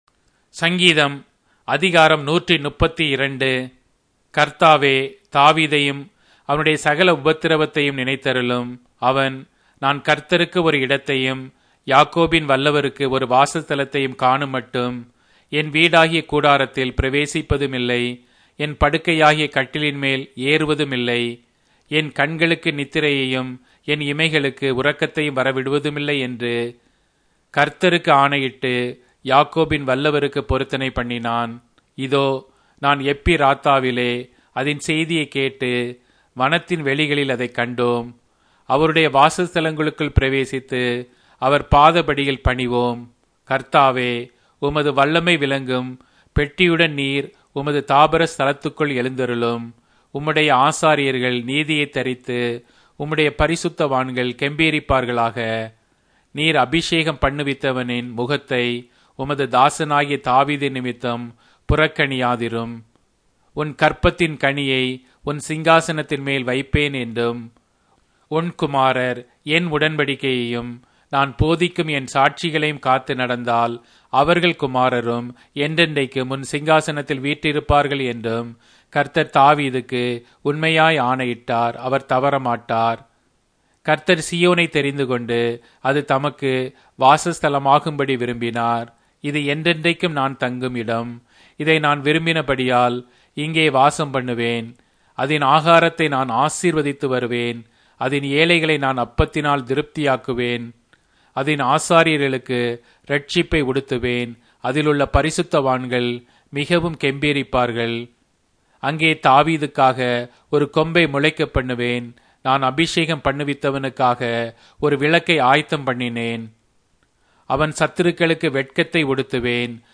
Tamil Audio Bible - Psalms 90 in Knv bible version